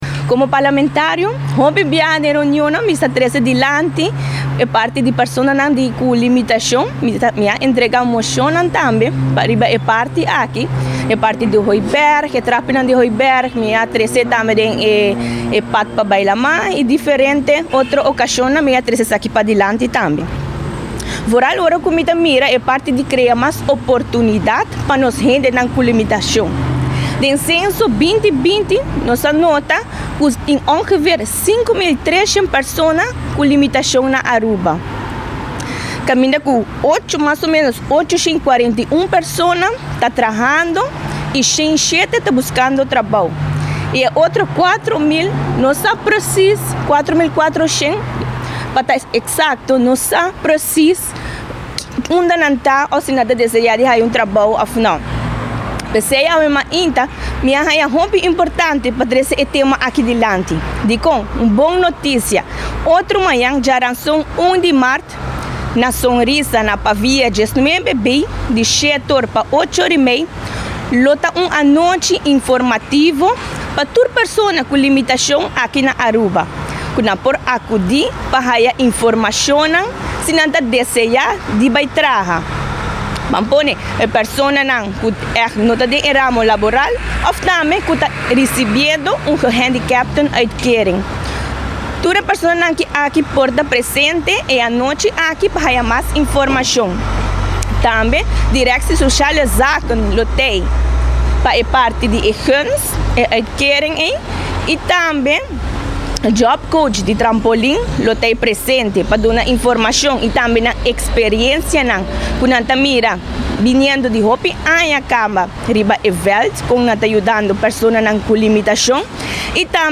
Den conferencia di prensa di fraccion di MEP Parlamentario Darlaine Guedez-Erasmus a elabora riba e personanan cu limitacion. Segun e parlamentario a haci entrega di diferente mocionnan den parlamento riba e structura di Aruba pa personanan cu limitacion no tin espacio.